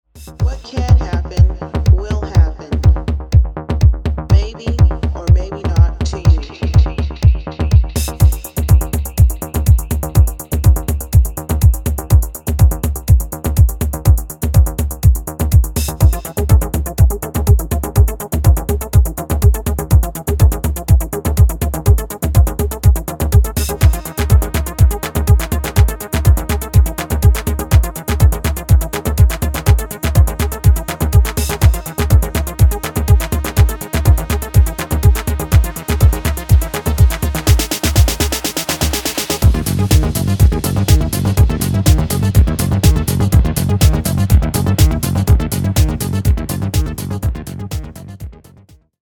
コシの効いたベースラインで淡々と迫るダークなアシッド・ハウス・グルーヴァー
全体的にかなりソリッドにまとめられ